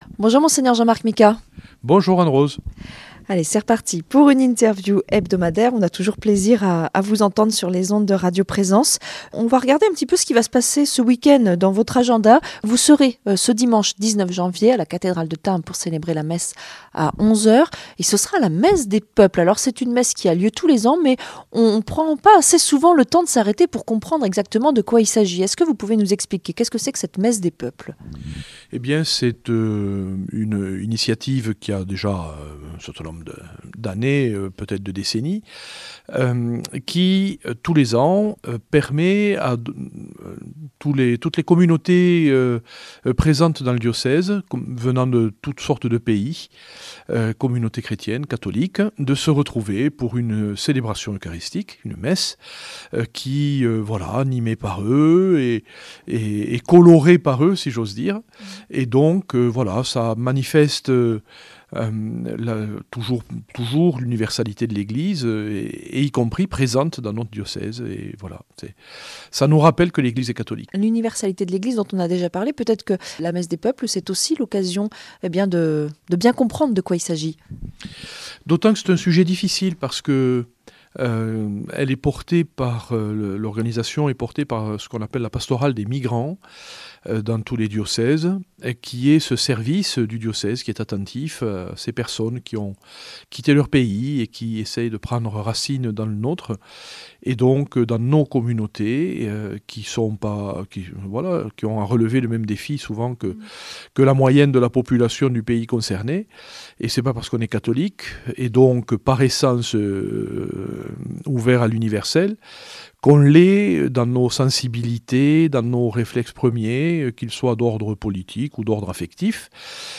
Entretien avec Mgr Micas - Évêque de Tarbes Lourdes du 17 janv.